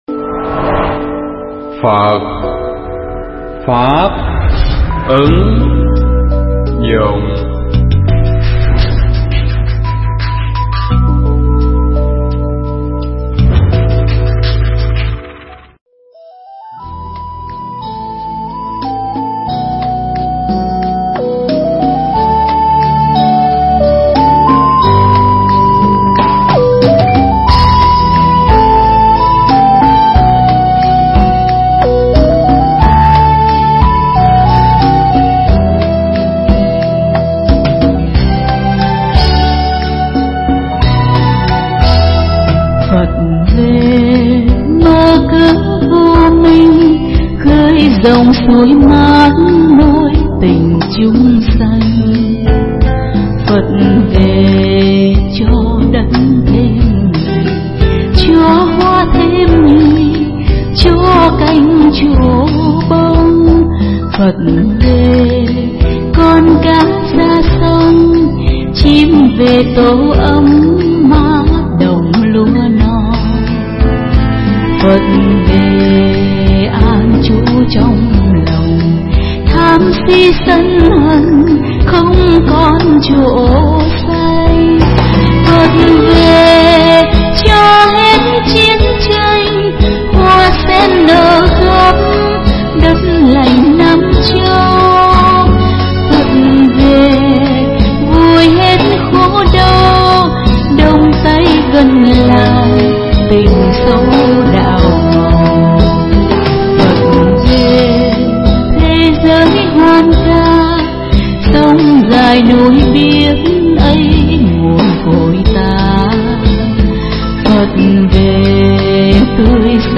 pháp thoại
tại chùa Hà Lâm (xã Tân Hà, huyện Lâm Hà, tỉnh Lâm Đồng)